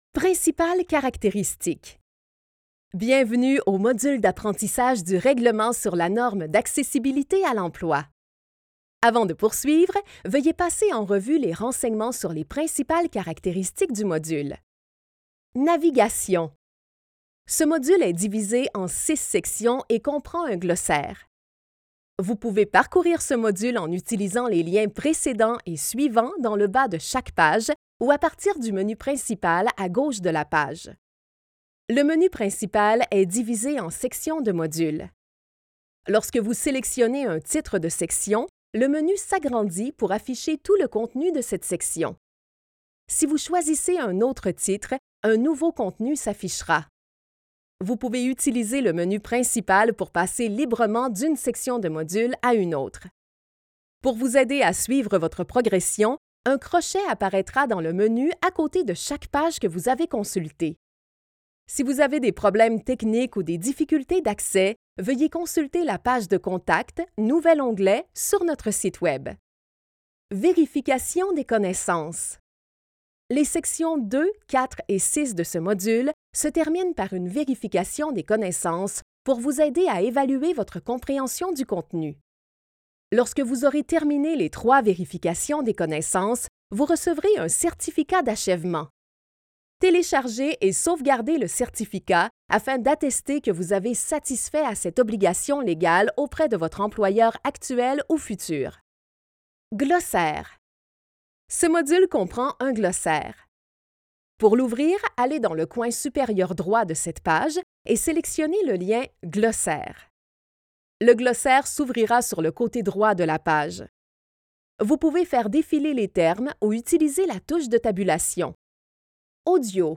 Module : Accessibilité à l’emploi Écouter Reculer Lecture Pause Arrêter Avancer 00:00 00:36 Mettre en sourdine Rétablir le son Remarque : Narration audio.